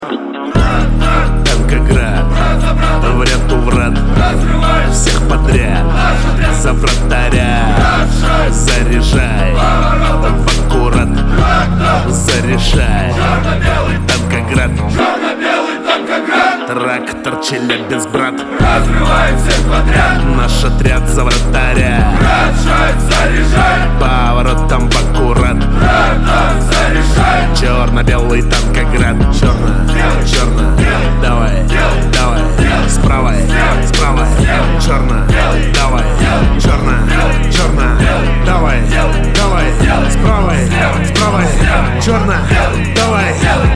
• Качество: 320, Stereo
громкие
русский рэп
качающие